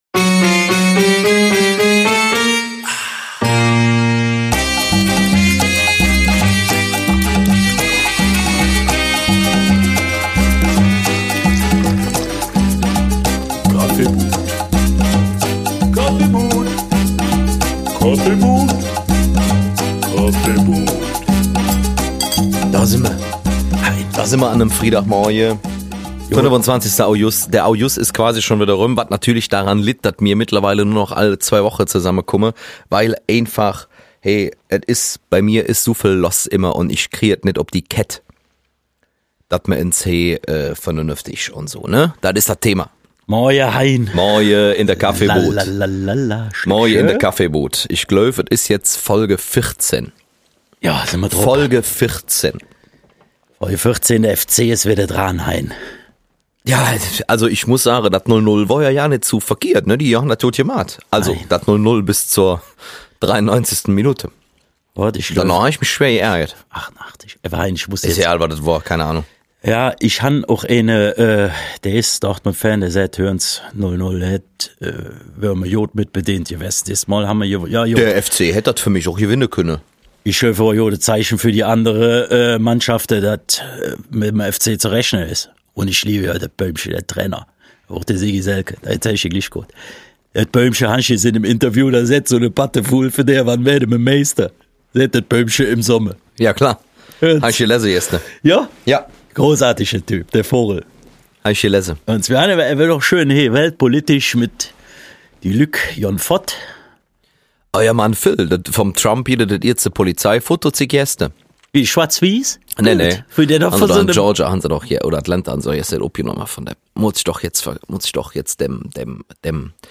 Jedenfalls sitzen hier zwei verschiedene Meinungen vor den Mikrofonen.